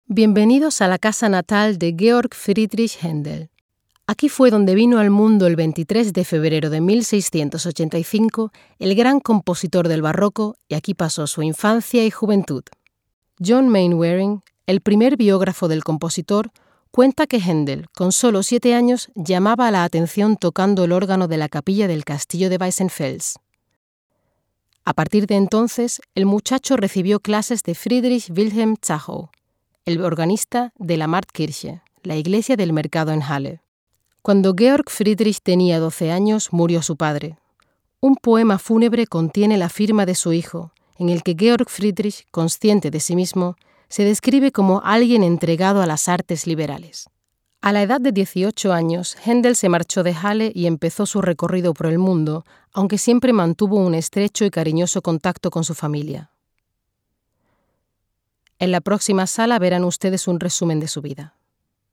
Spanisch. 2014 SPRACHEN Deutsch: Flieβend (C2) Englisch: Flieβend (C1) Italienisch: Gut (A2) Spanisch: Muttersprache (europäisch) Akzente: Castellano, Andalusisch, Mexikanisch, Argentinisch Stimmalter: 30 – 40 Stimme: voll, frisch, dynamisch, warm, facettenreich, weich.
Sprechprobe: Industrie (Muttersprache):